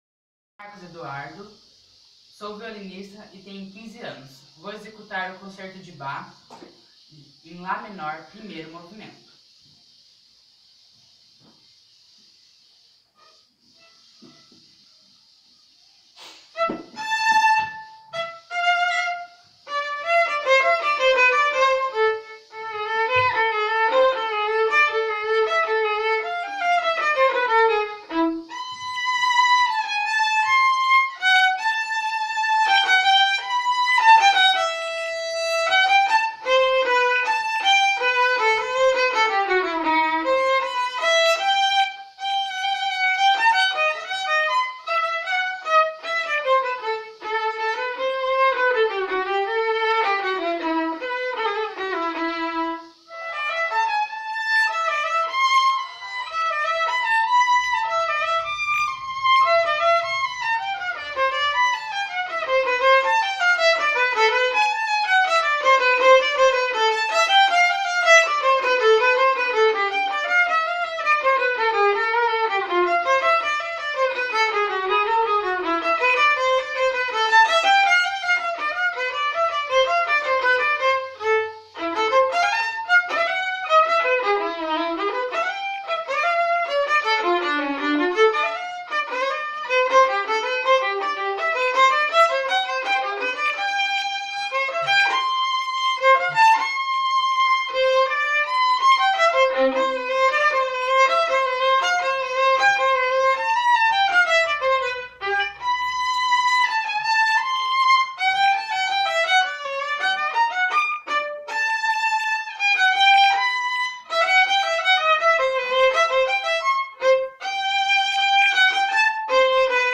concerto in a minor violin
bach-_concerto_in_a_minor_violin.mp3